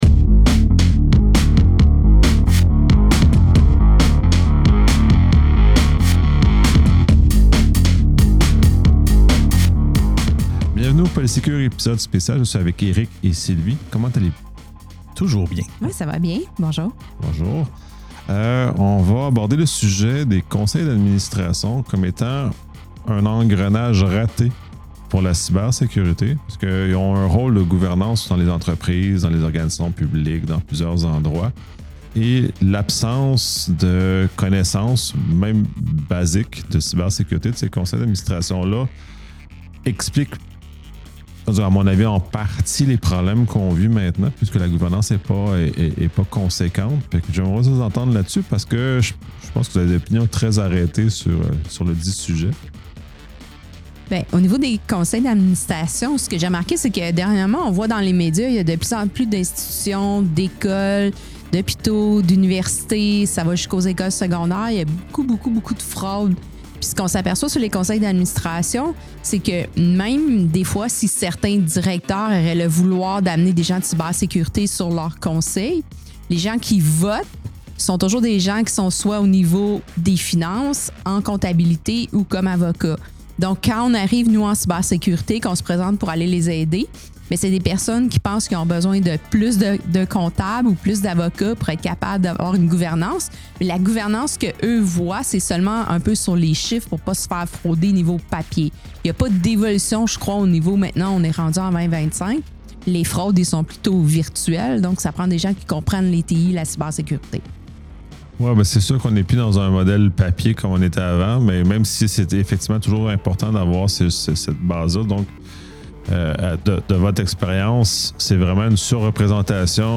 Ce podcast spécial réunit trois experts pour discuter d’un enjeu crucial : les conseils d’administration comme engrenage raté de la cybersécurité. L’hypothèse centrale avancée est que l’absence de connaissances même basiques en cybersécurité au sein de ces instances de gouvernance explique en partie les problèmes actuels auxquels font face les organisations québécoises et canadiennes.